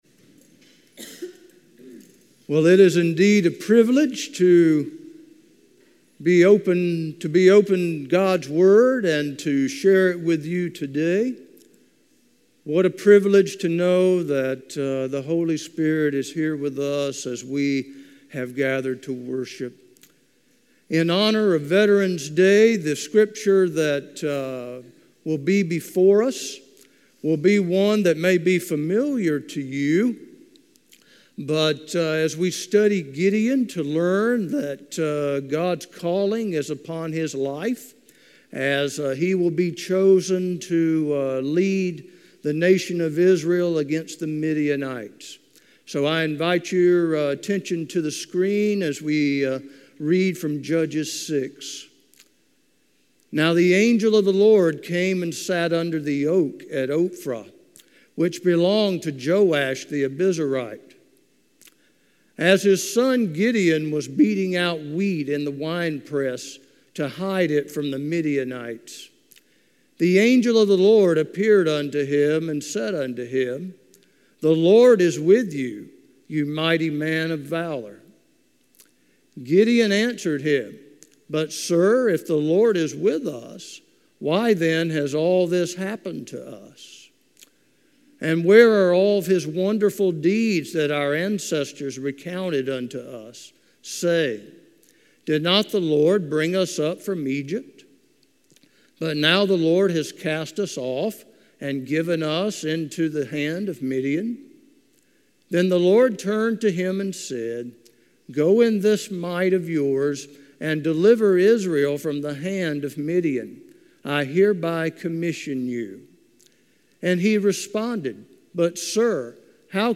A message from the series "Uncategorized."